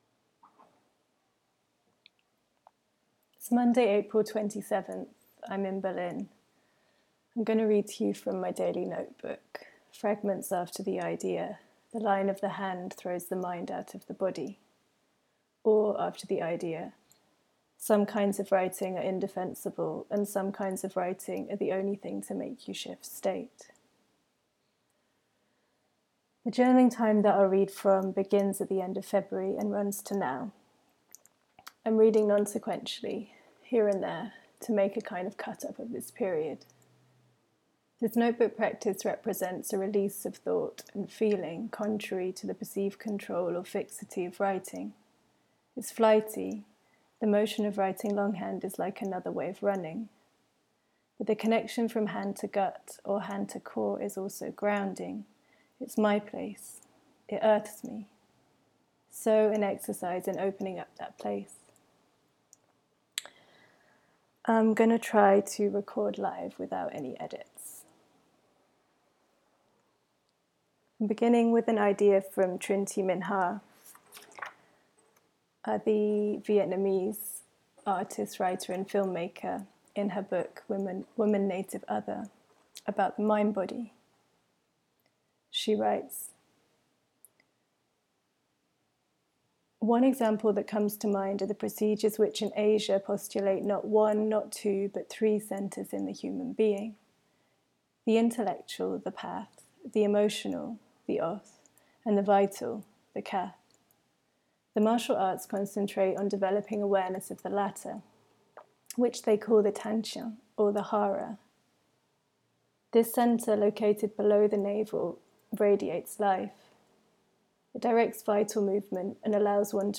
I read fragments from the end of February to the end of April 2020, here and there; paragraphs, lines, or words from flicked through pages, self-censoring as I stumble and flow.